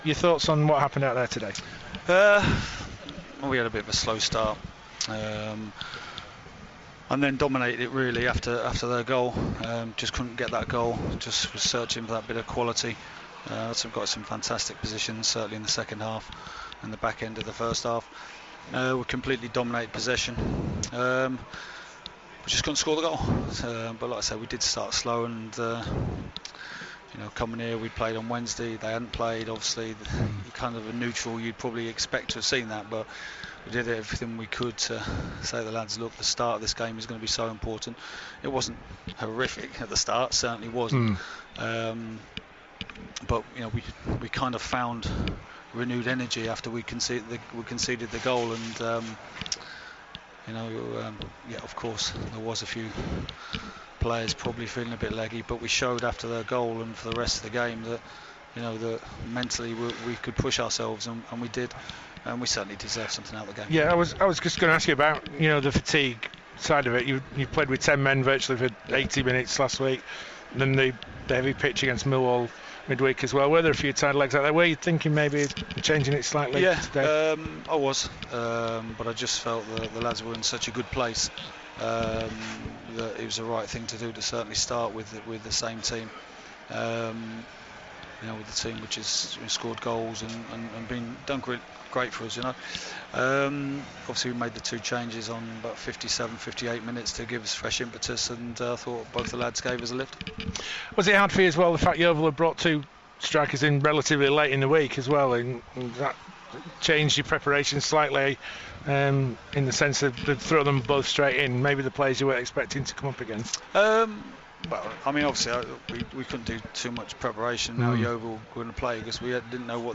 Phil Parkinson post match reaction.